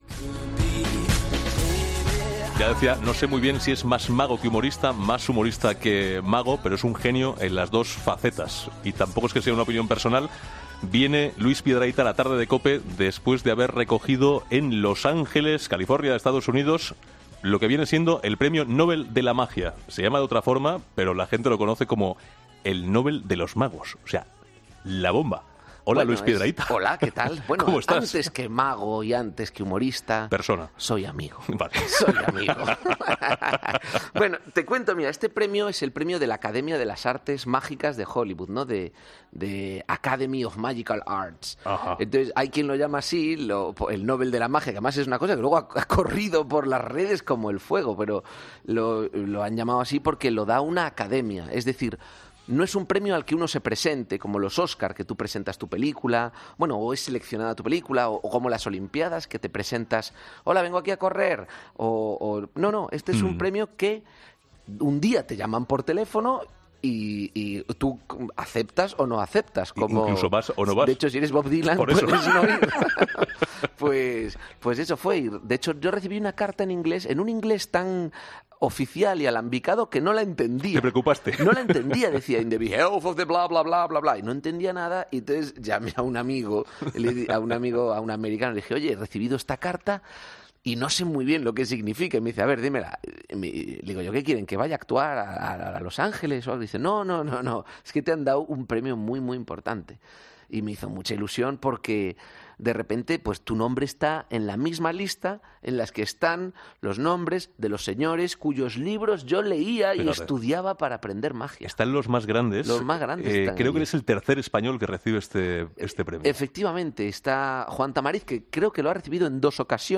Luis Piedrahita, humorista y mago, en 'La Tarde'